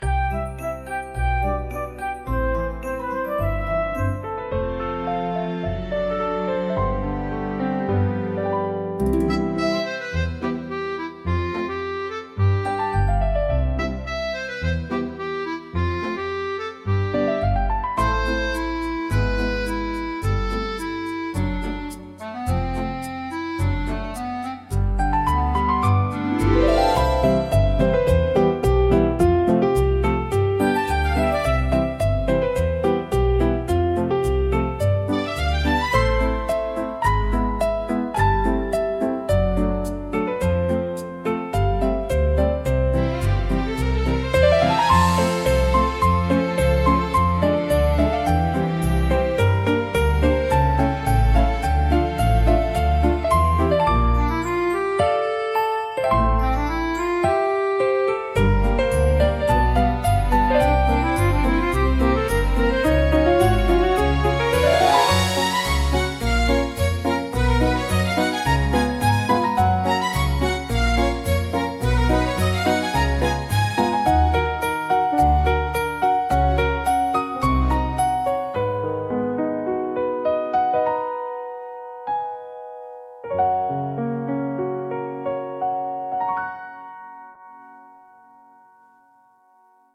嬉しいジャンルは、明るく陽気な楽曲で、軽快なリズムとポップなメロディが特徴です。